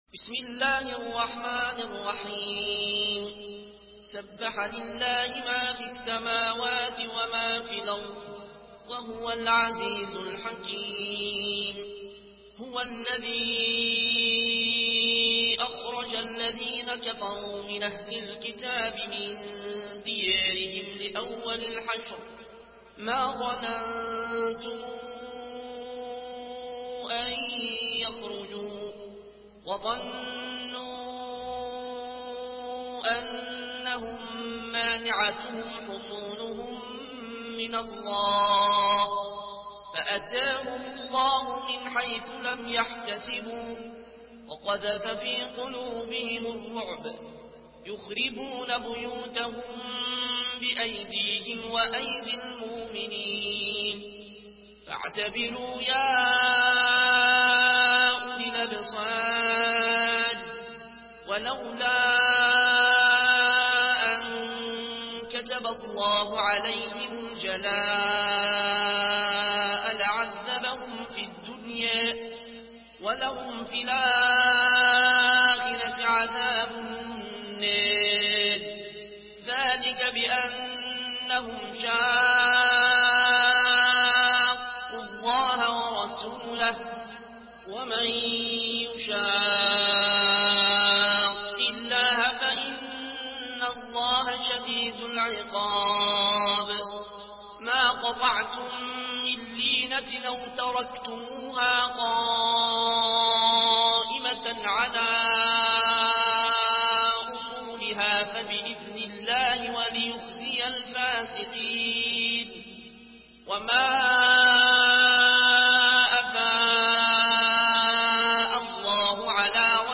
in Warsh Narration
Murattal